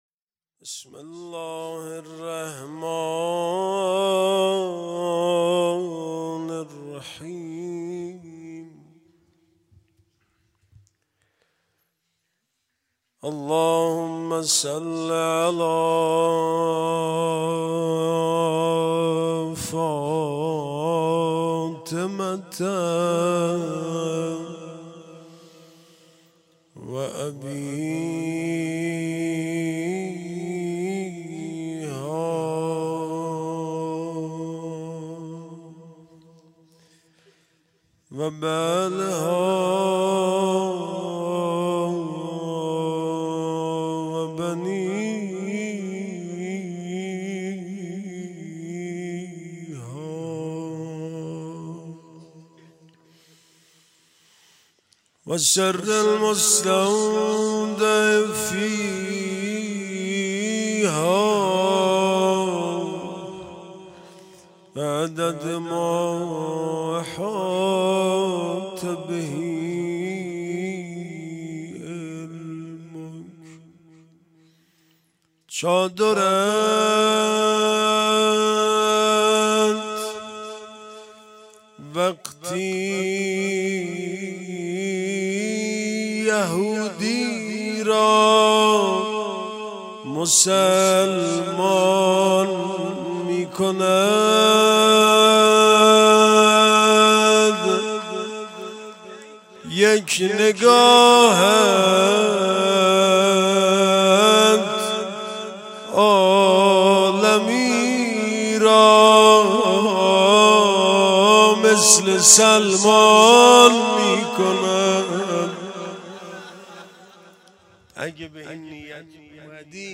✅روضه شب اول فاطمیه دوم - بخش اول